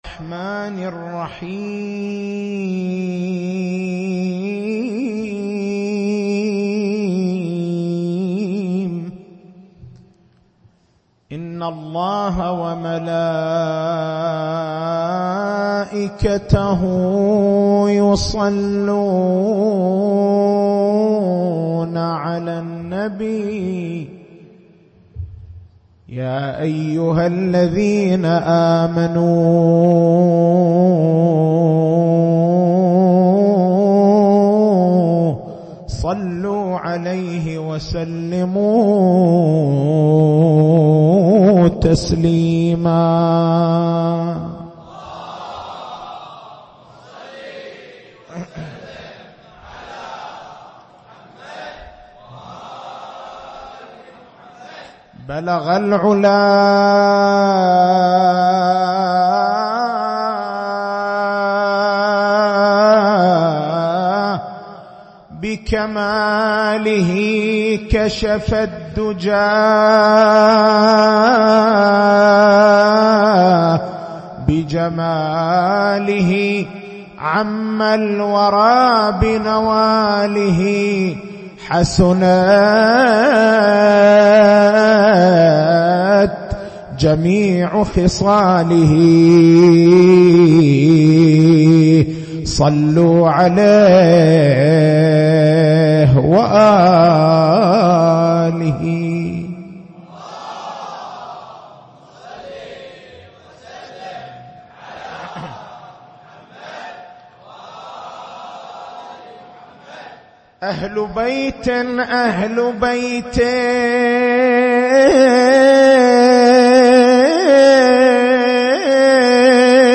تاريخ المحاضرة: 15/09/1435 نقاط البحث: ما هو سرّ تعدّد ألقاب المعصومين (ع)؟